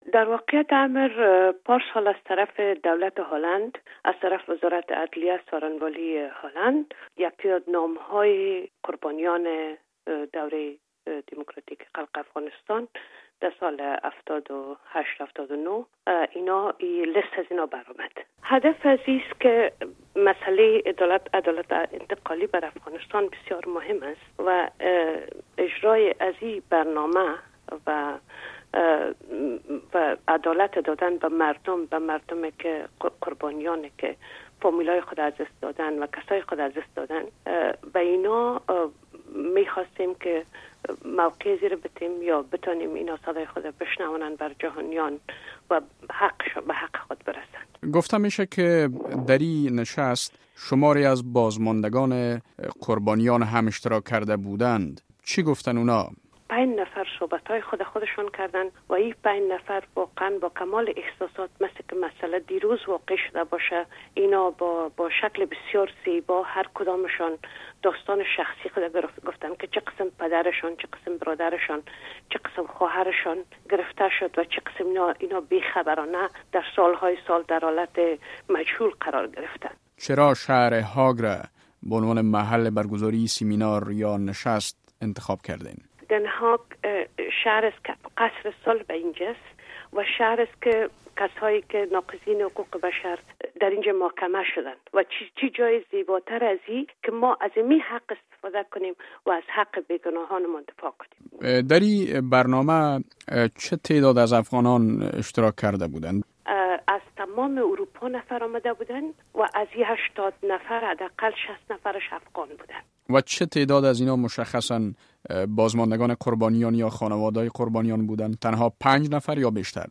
مصاحبه در مورد گردهمایی بازمانده گان قربانیان 1357 و 1358 در شهر هاگ